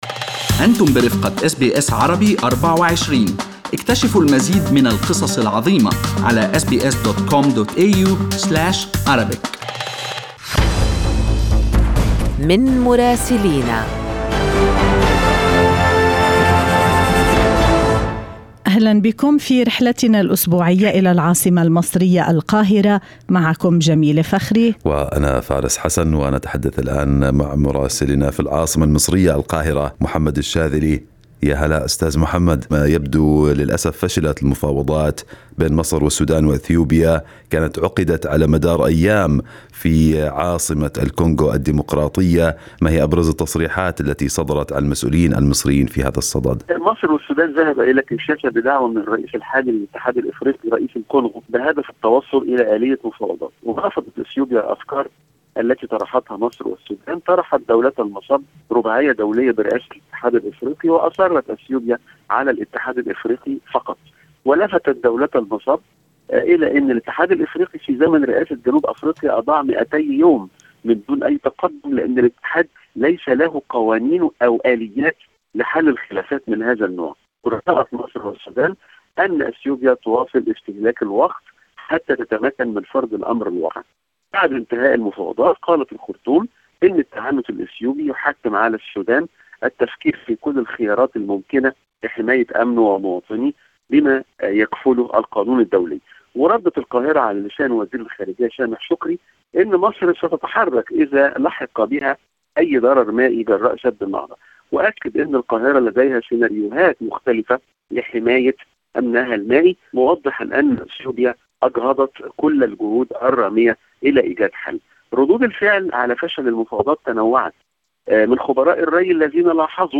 من مراسلينا: أخبار مصر في أسبوع 7/4/2021